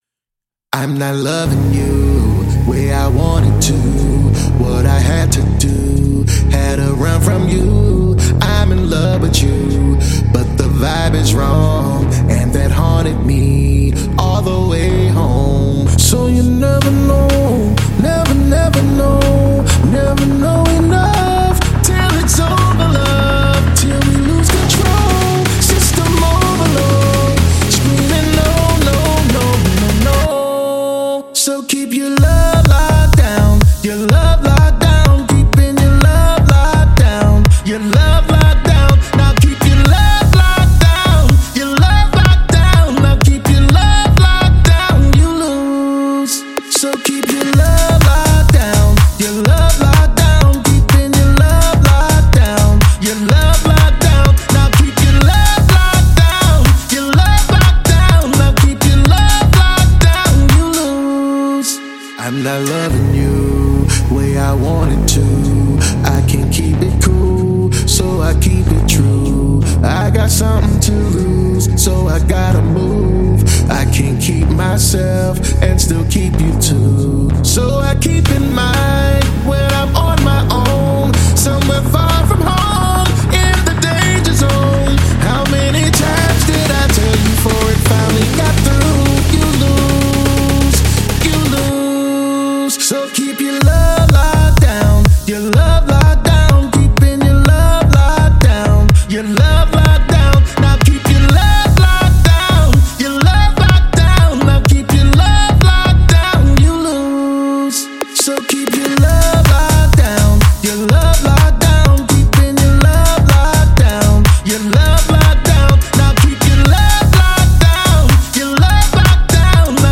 EDM